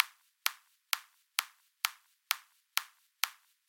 踢腿循环130bpm
描述：一些Techno循环在130 Bpm。